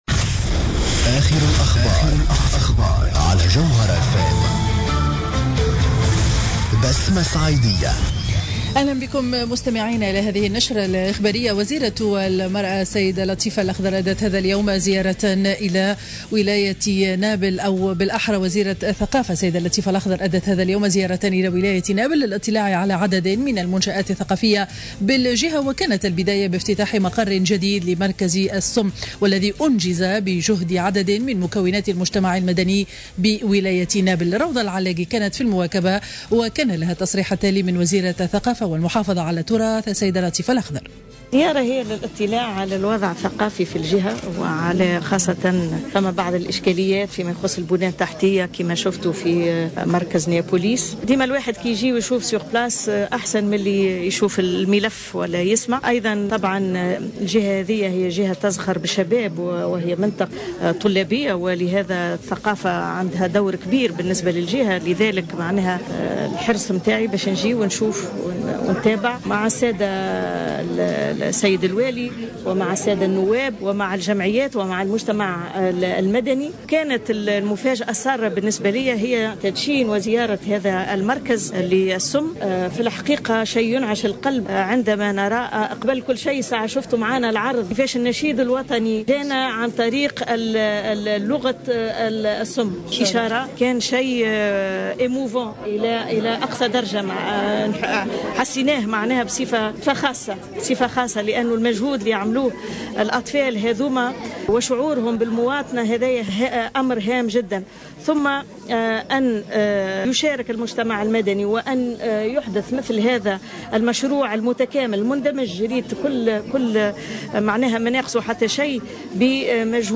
نشرة أخبار منتصف النهار ليوم الاثنين 22 جوان 2015